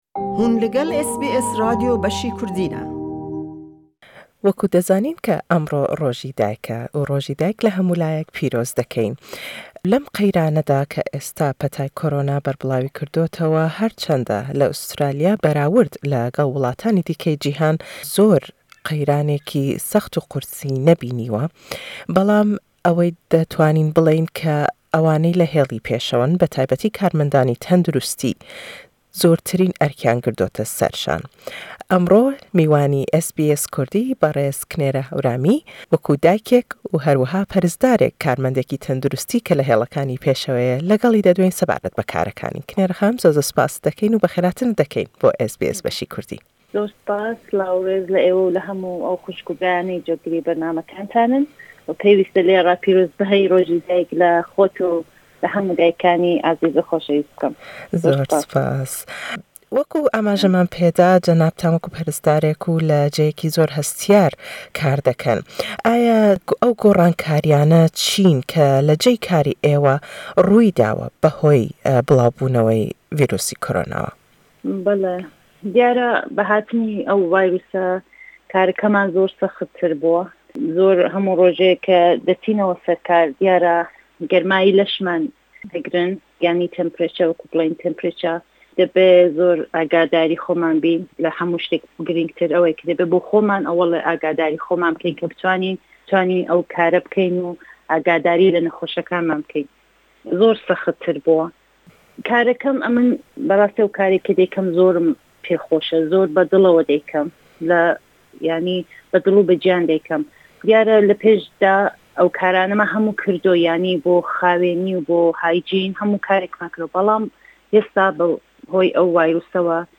Le em lêdwaneda bas le karekanî xoyman bo dekat û ew gorrankarîyaney ke hatûnete pêşewe be hoyî qeyranî vîrusî-korona we û tirsî ew le rîsk bo ser binemallekey